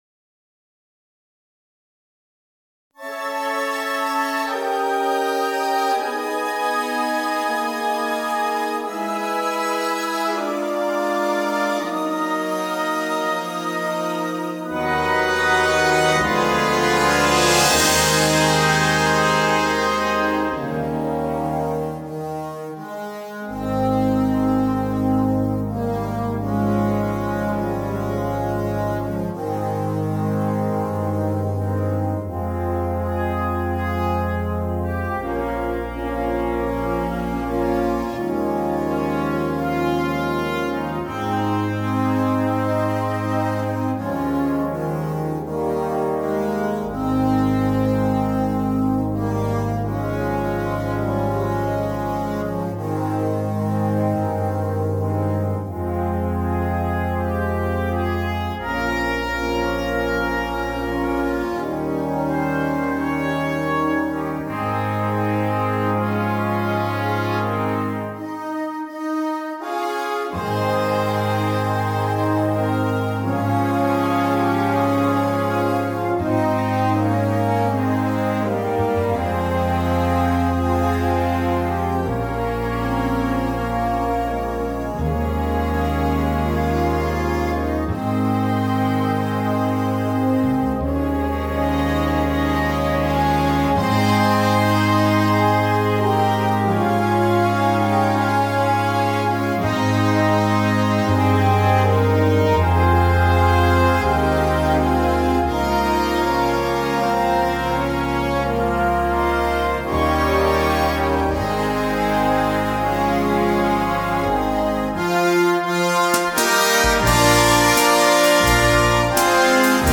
The melody is a Swedish folk song.
CategoryConcert Band
Flutes 1-2
Bb Trumpets 1-2-3
Drum Kit